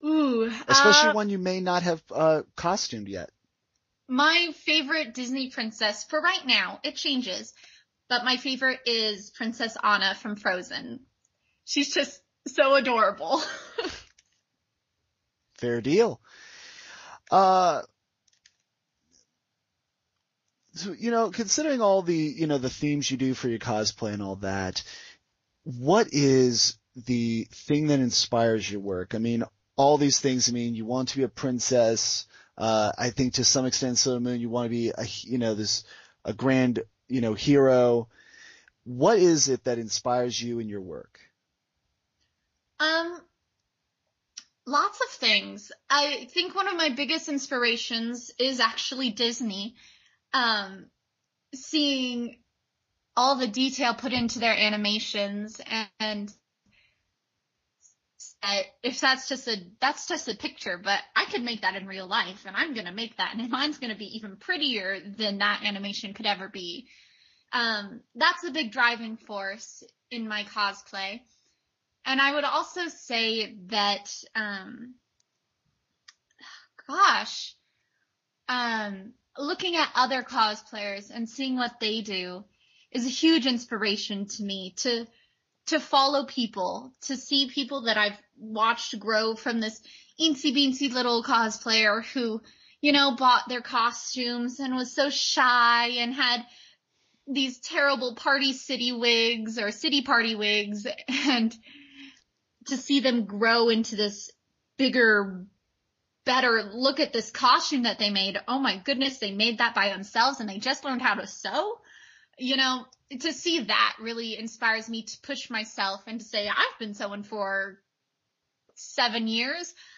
Sailor Senshi Brought to Life- Interview with Otakon Viz Senshi